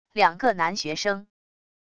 两个男学生wav音频